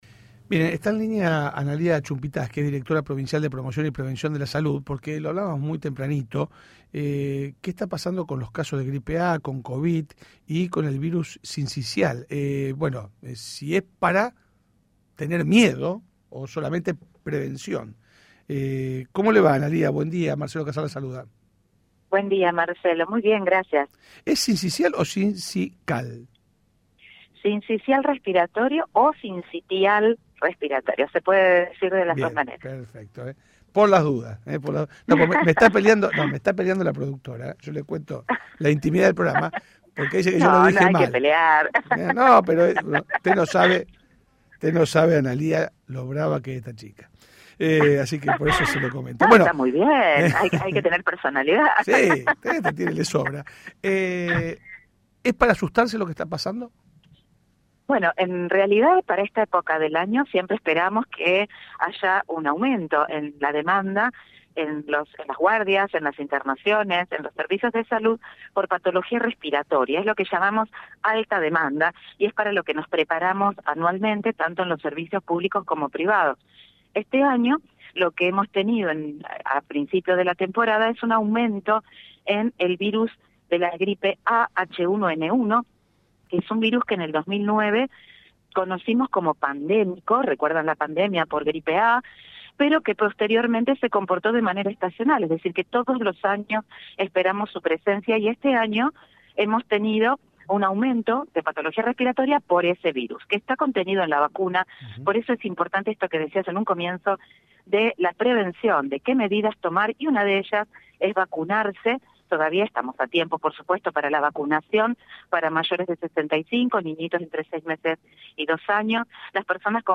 Analía Chumpitaz, directora provincial de promoción y prevención de la salud, habló en el programa La Barra de Casal a raíz del incremento en la cantidad de casos de gripe A, covid 19 y virus sincisial respiratorio y dijo que es parte de lo esperable para la temporada, y destacó la importancia de la prevención para estos casos.